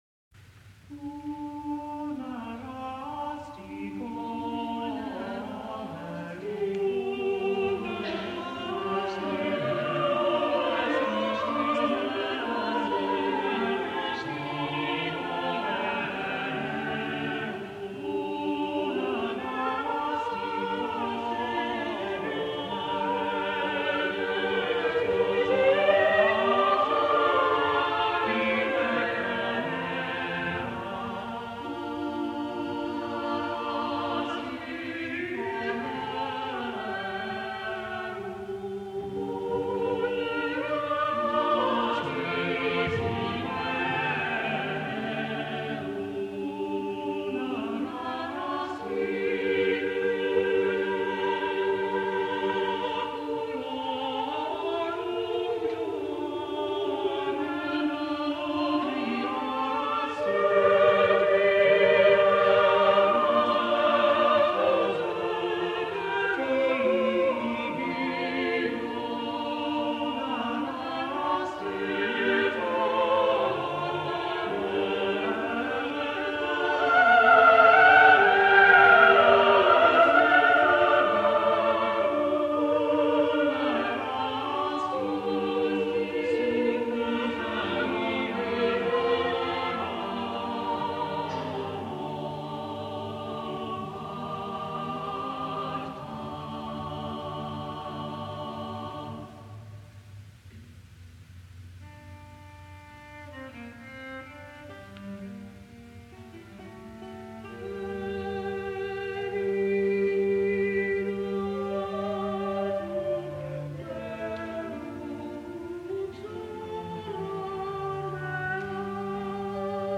The text is a short excerpt from the Song of Songs, which, being scripture, provided an excuse for passionate love songs to be introduced into religious services.  This motet is a superb example of how by the early 1500s vocal polyphony had acquired a fluency of technique unsurpassed to this day.